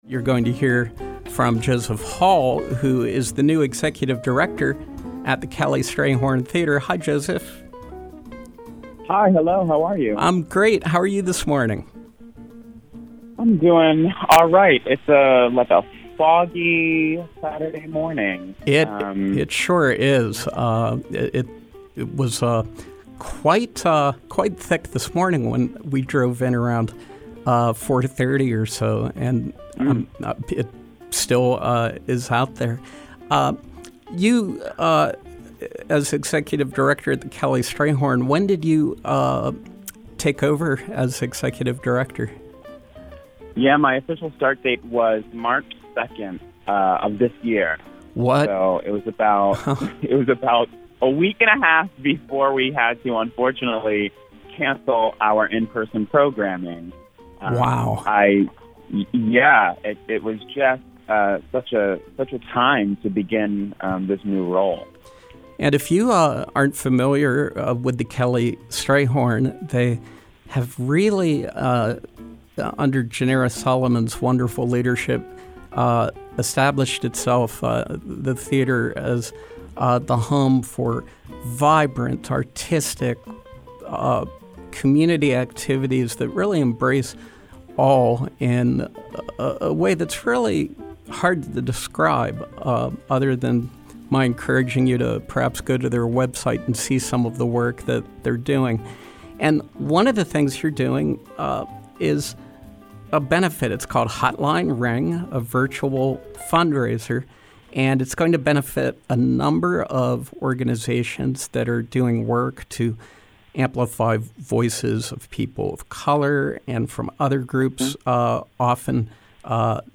Home » Featured, Interviews